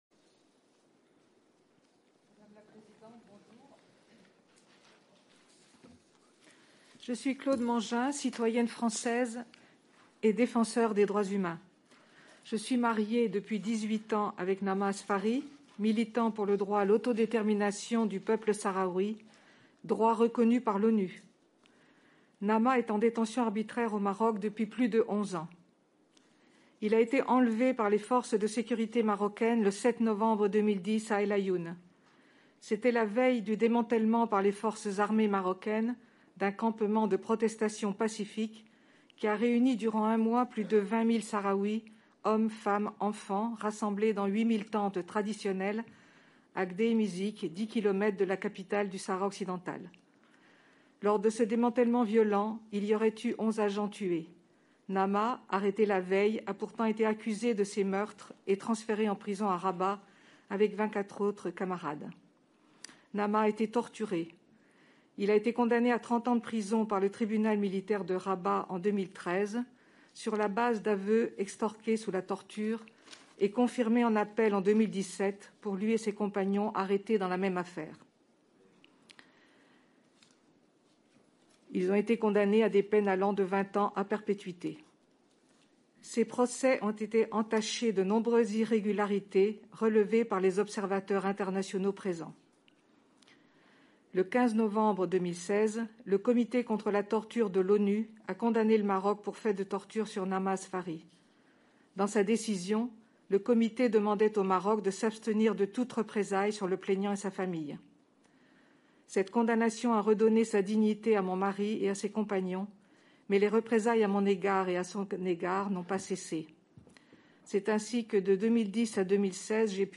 احتضنت الجمعية الوطنية الفرنسية “البرلمان” جلسة علنية، لفحص الهيئة سياسة الحكومة الفرنسية تجاه قضية الصحراء الغربية، بدعوة من تحالف اليسار الديمقراطي والجمهوريين دامت زهاء ساعة، استمع خلالها النواب إلى مداخلات قدمها وفد جبهة البوليساريو حول الموضوع.
تصريح